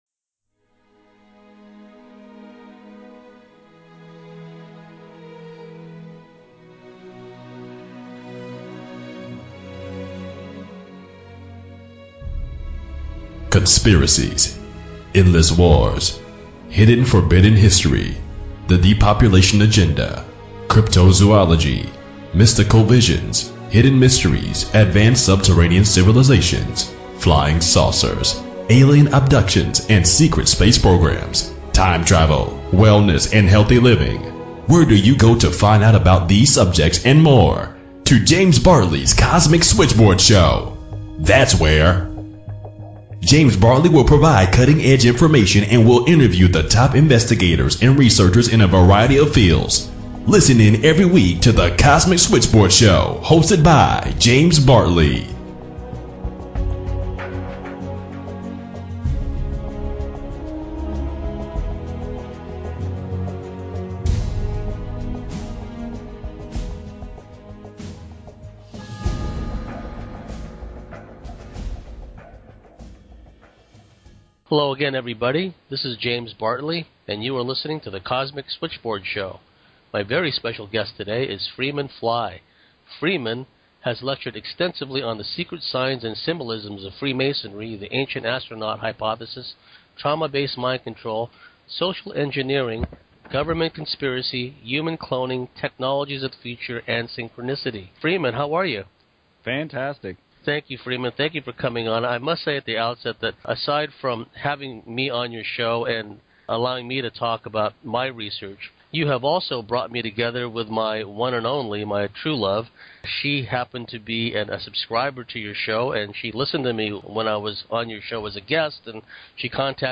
9/11 Special Interview